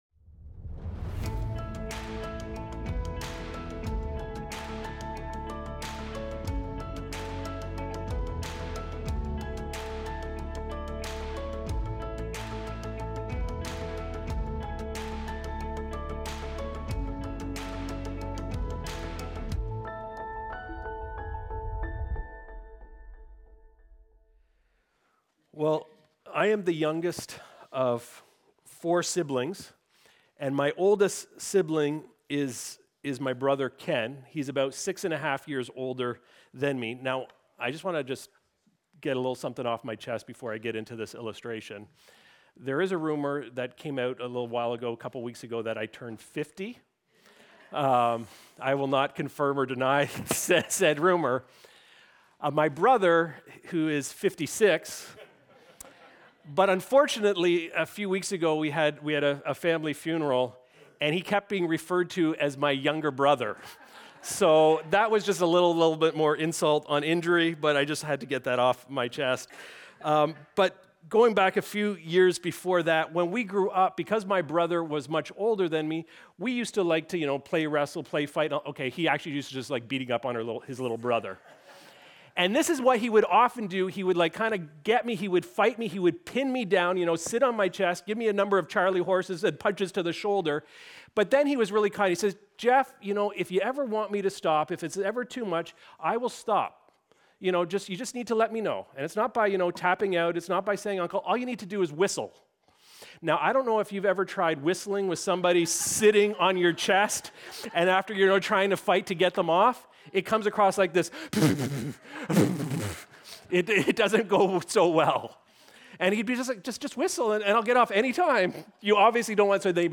Recorded Sunday, March 1, 2026, at Trentside Fenelon Falls.